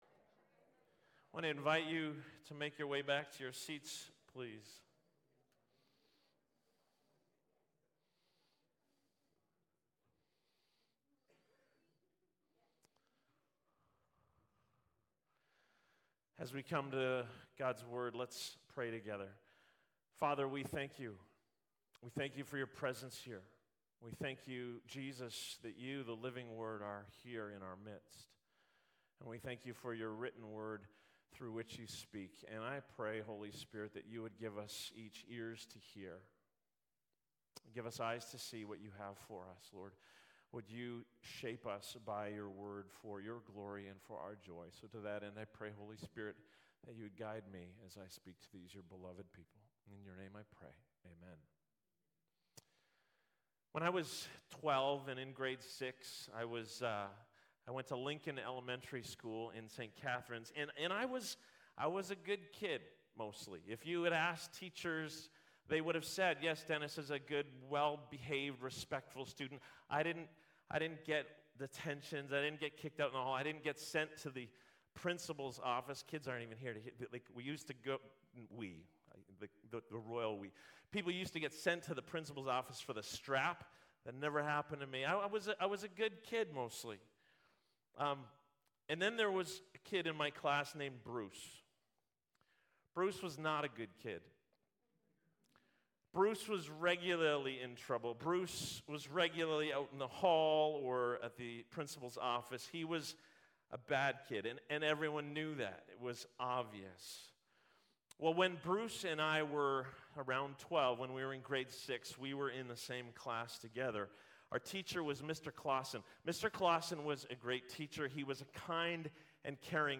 Sermons | Sunrise Community Church